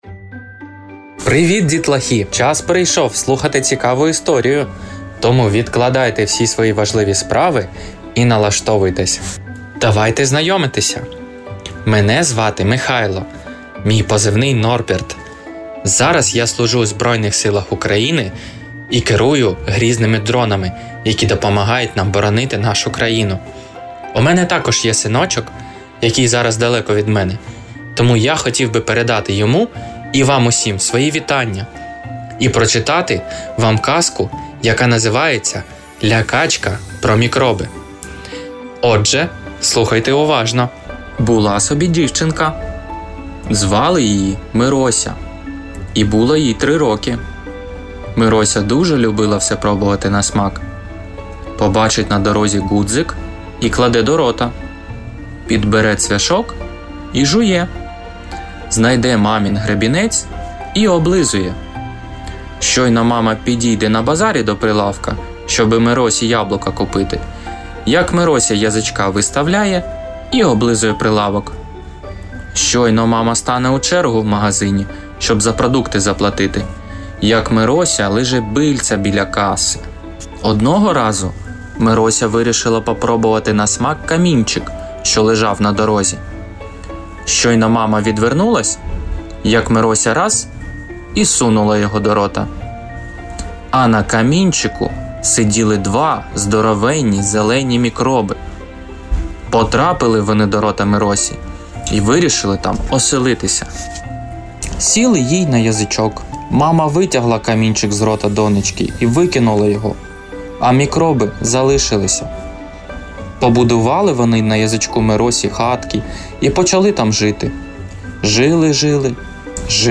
Історії від сучасних українських авторів зачитають батьки, які попри будь-яку відстань завжди поруч.
Цю історію для свого сина та для українських дітлахів зачитав захисник з позицій.